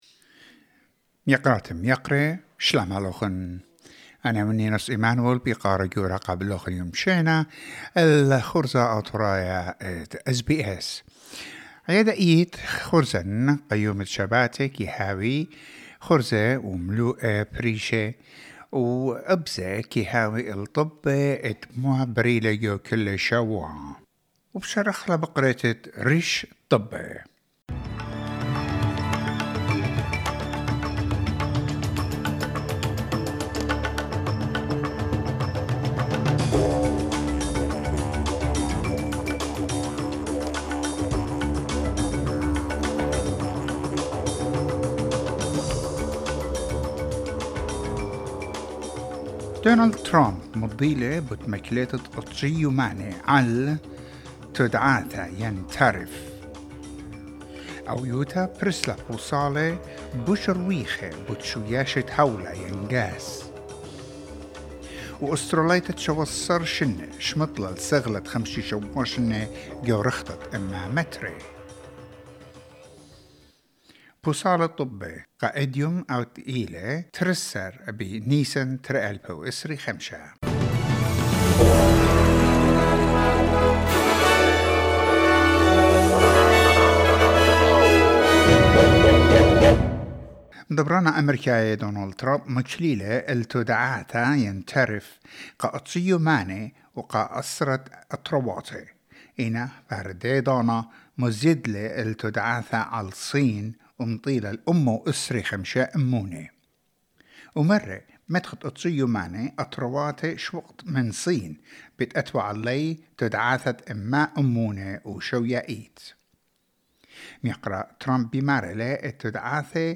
SBS Assyrian: Weekly news wrap